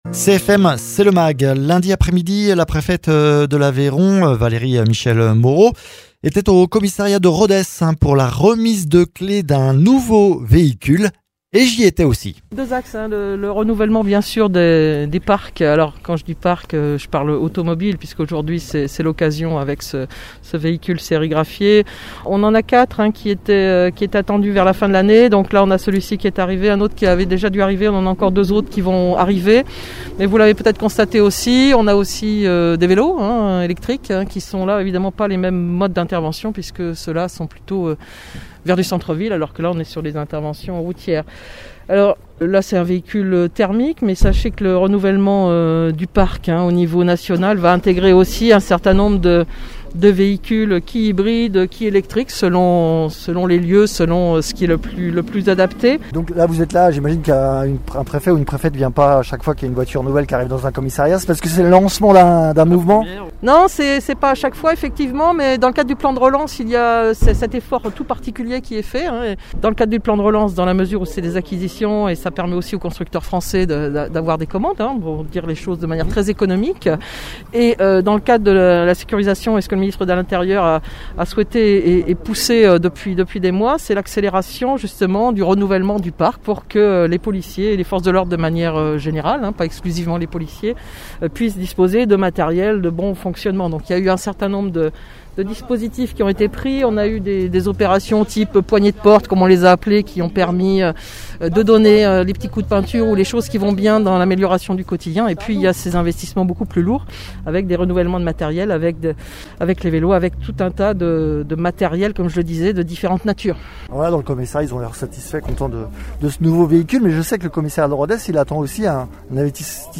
Interviews
Invité(s) : Valérie Michel Moreau, préfète ; Loïc Jezequel, Directeur départemental de la sécurité public de l’Aveyron